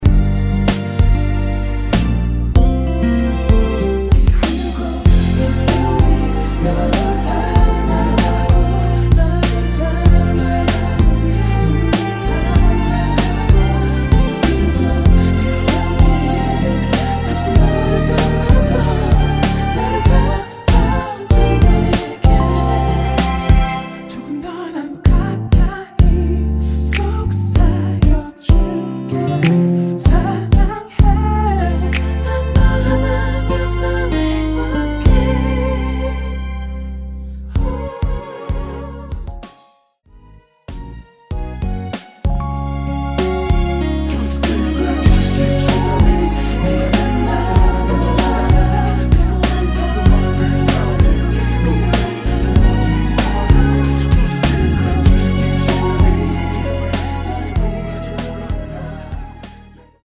코러스만 포함된 MR이며
앞부분30초, 뒷부분30초씩 편집해서 올려 드리고 있습니다.